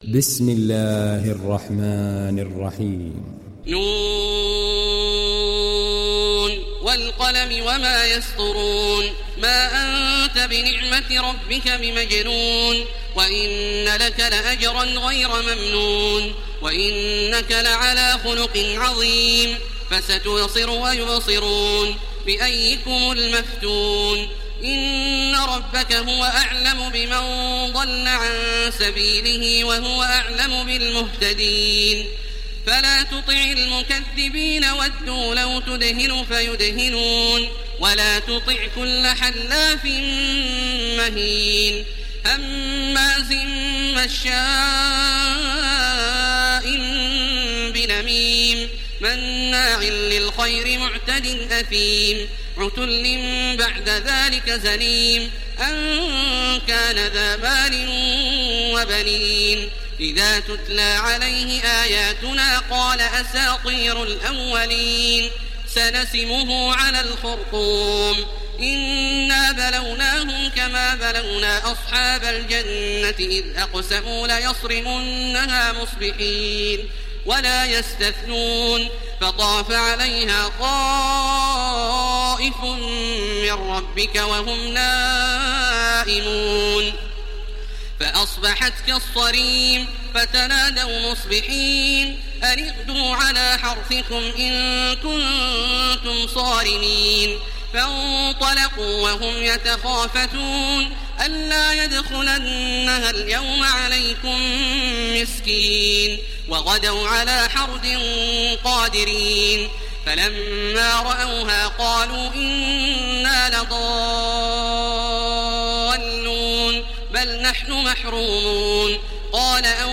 دانلود سوره القلم تراويح الحرم المكي 1430